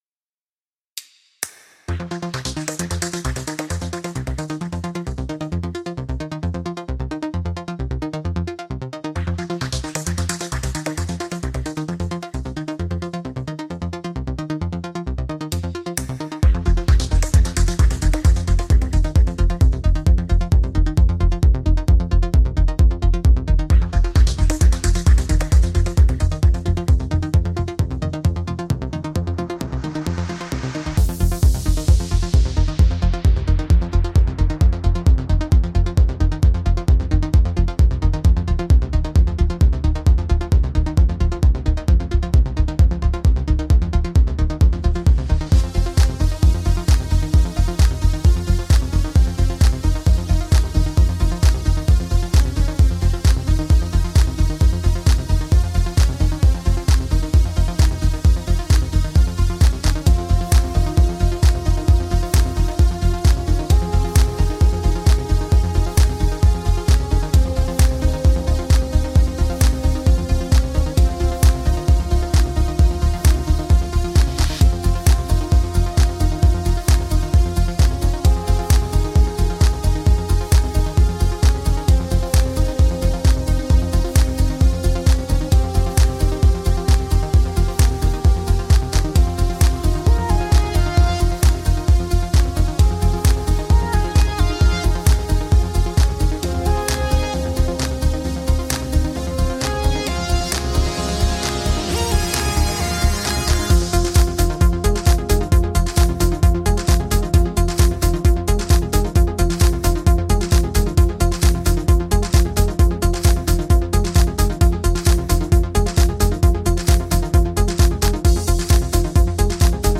Tagged as: Electronica, Techno